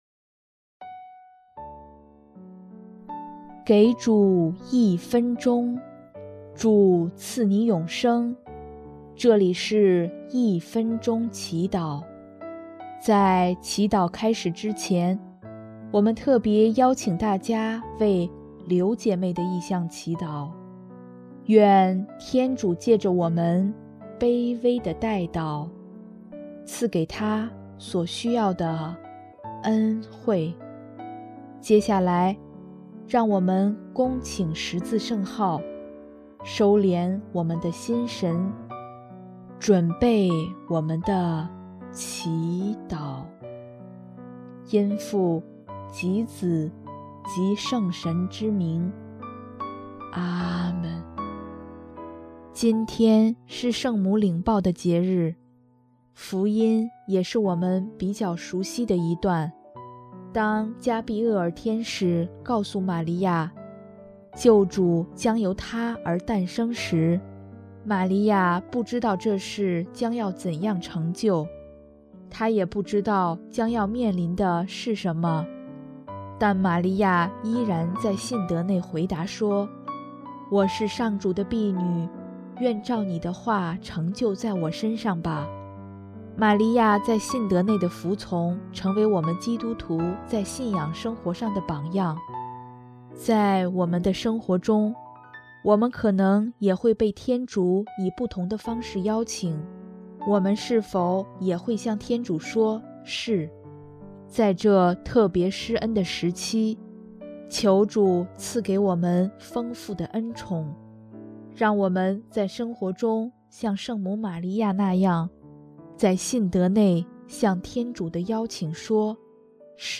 【一分钟祈祷】|3月25日 向主说:“是”